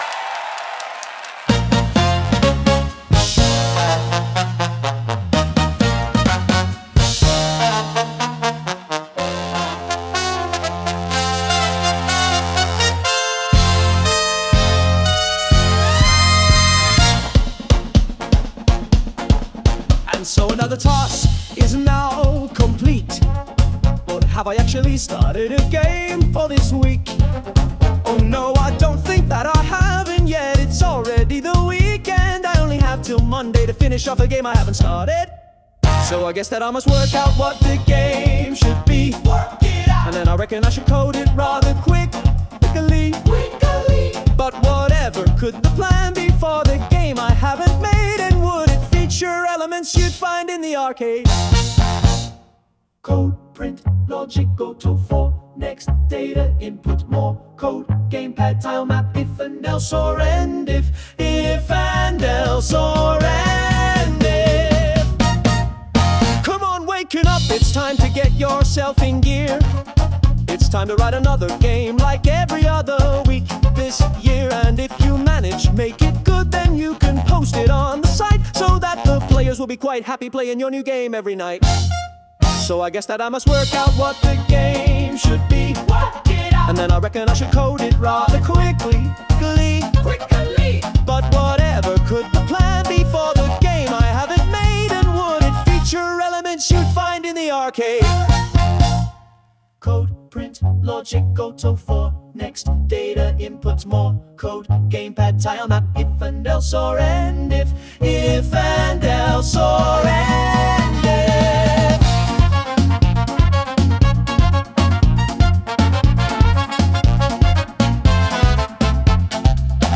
Code_Print_Logic_Goto_(Remix)_mp3.mp3